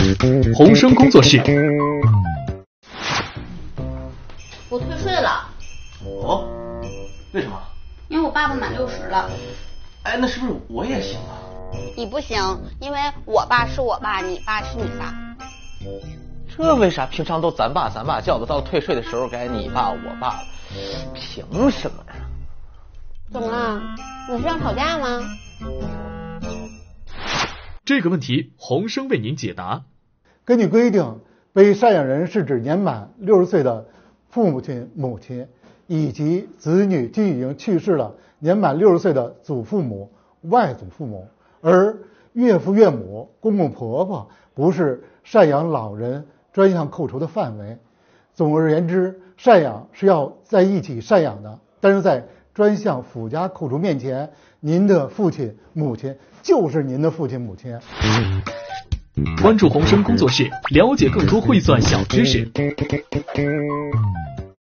今年全国税收宣传月期间，国家税务总局北京市房山区税务局“红生工作室”倾力打造的“红生工作室小剧场”如期上线，工作室用幽默的情景剧和专业的答疑，让税收宣传“走红”房山。